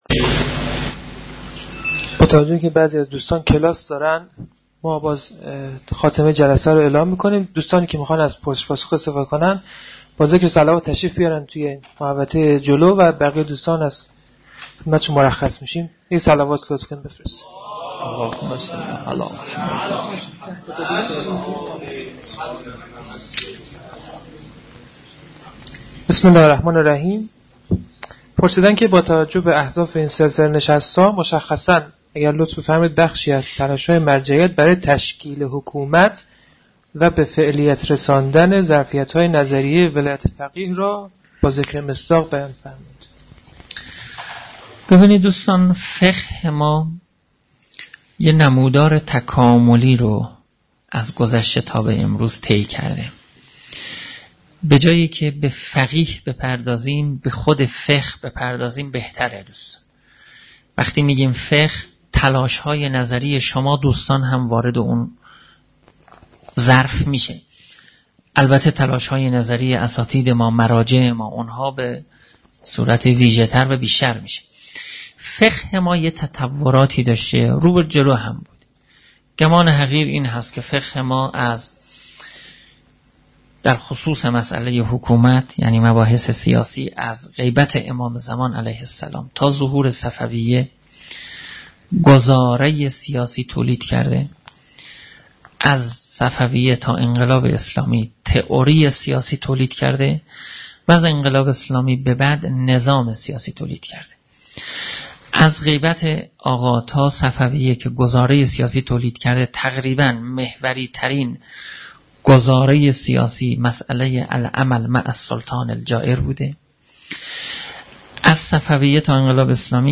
2.پرسش-و-پاسخ.mp3